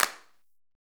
PRC XCLAP0RR.wav